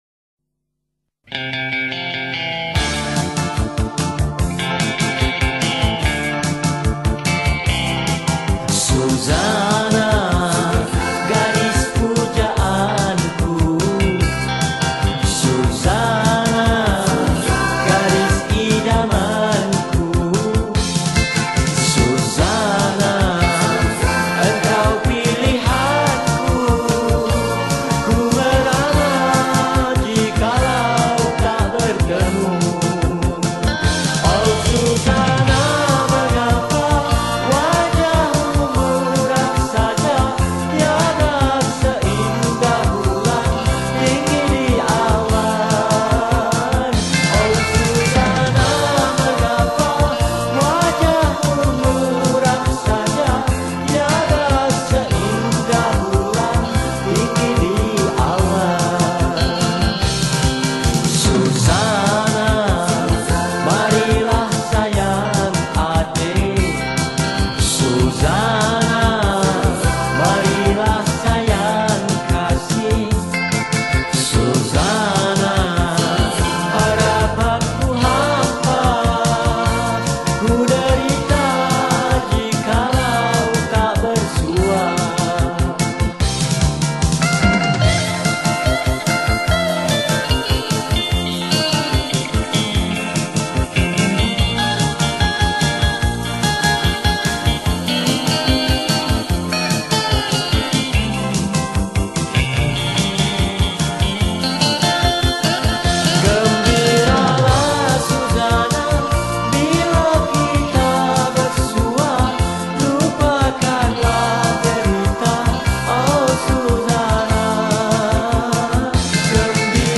Pop Yeh Yeh
Malay Song